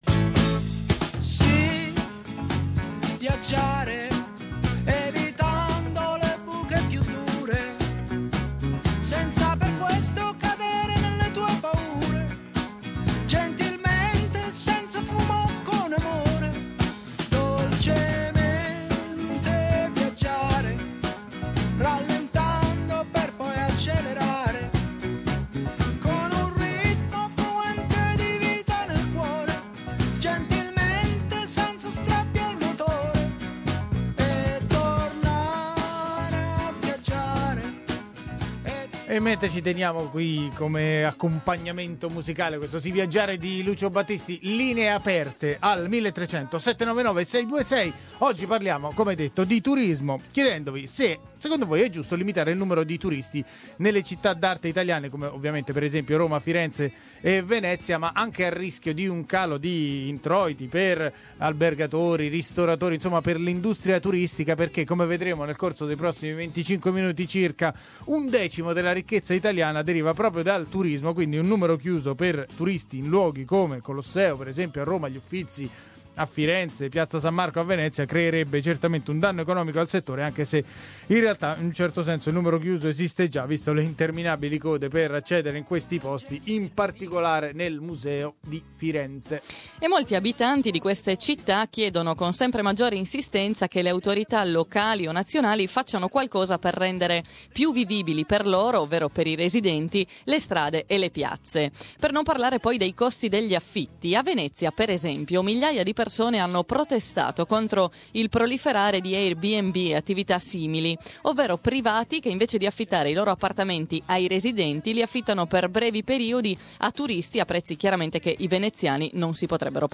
Le città d'arte italiane, come Venezia, dovrebbero porre un limite al numero di turisti che le visitano quotidianamente? Lo abbiamo chiesto ai nostri ascoltatori.